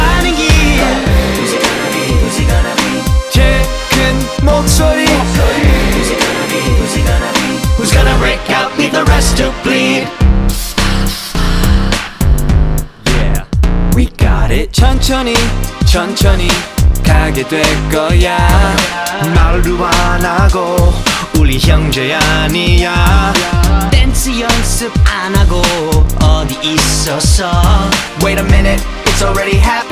• K-Pop